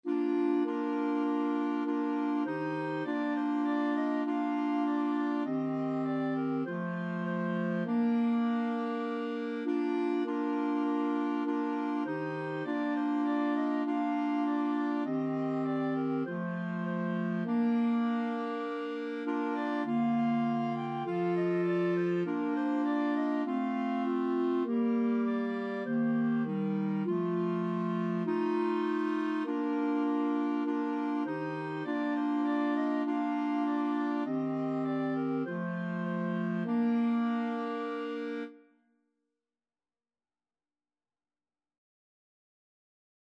Christmas Christmas Clarinet Quartet Sheet Music O Little Town of Bethlehem
Free Sheet music for Clarinet Quartet
Bb major (Sounding Pitch) C major (Clarinet in Bb) (View more Bb major Music for Clarinet Quartet )
4/4 (View more 4/4 Music)
Clarinet Quartet  (View more Easy Clarinet Quartet Music)
Traditional (View more Traditional Clarinet Quartet Music)